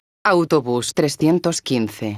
megafonias exteriores